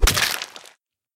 among us slice.ogg